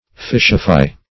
Fishify \Fish"i*fy\, v. t. To change to fish.